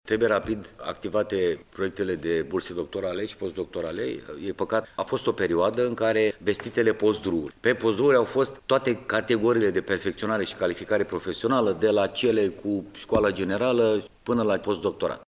Totodată, ministrul Lucian Georgescu a mai spus că ar trebui reactivate sistemele de burse doctorale şi post-doctorale din cadrul programului POS-DRU: